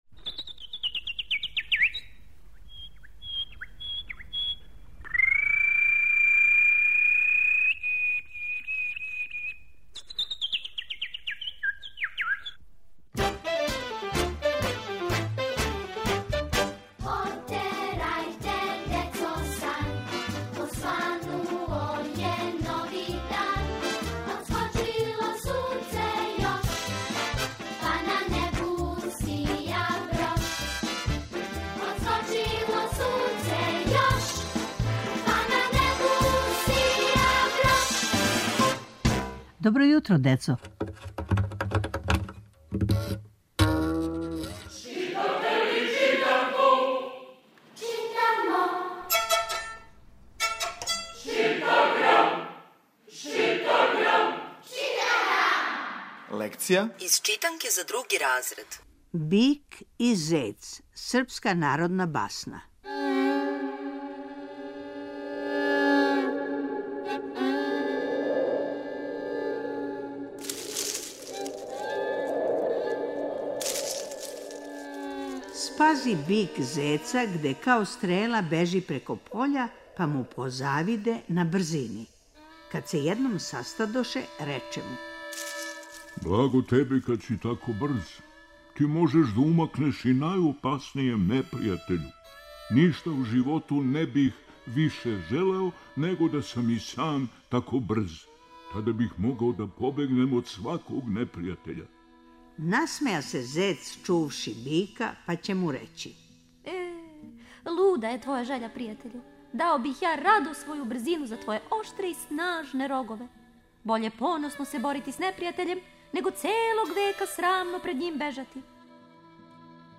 Сваког понедељка у емисији Добро јутро, децо - ЧИТАГРАМ: Читанка за слушање. Ове недеље - други разред, лекција: "Бик и зец", народна басна.